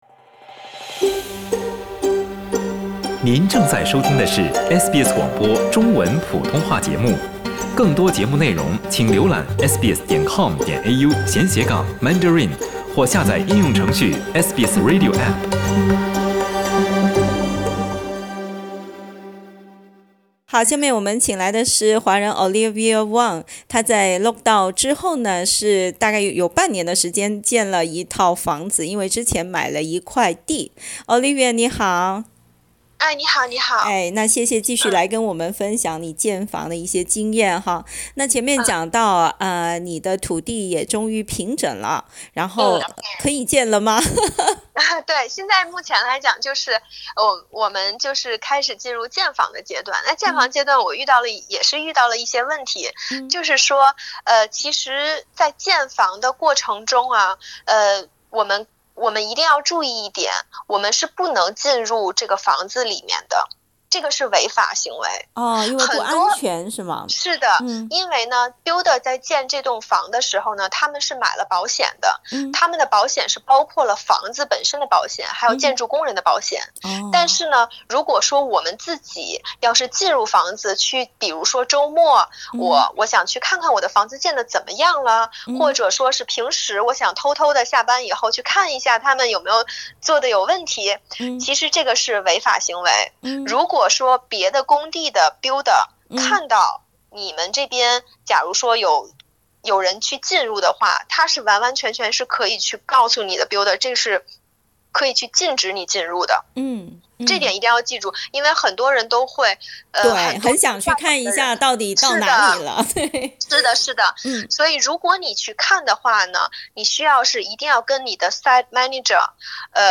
（请听采访，本节目为嘉宾个人体验，仅供参考） 澳大利亚人必须与他人保持至少1.5米的社交距离，请查看您所在州或领地的最新社交限制措施。